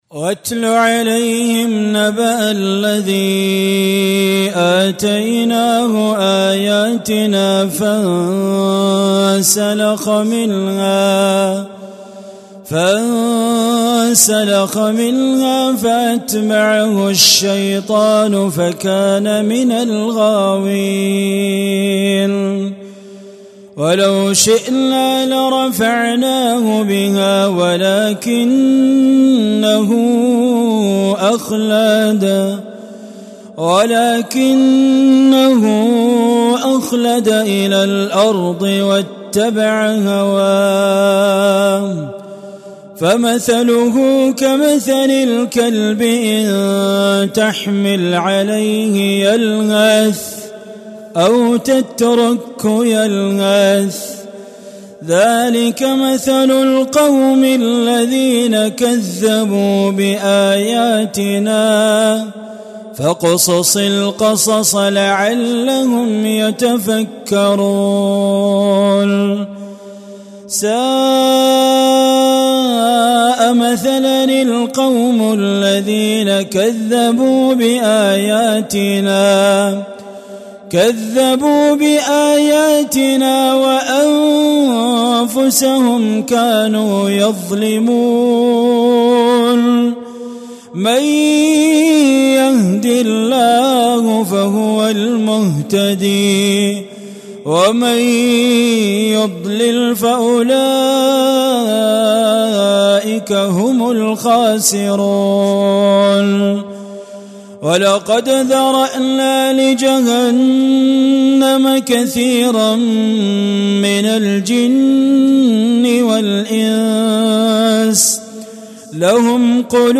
القرآن الكريم برواية حفص عن عاصم
تلاوة
ويأتينا هذا الأداء بأسلوب شيق ومؤثر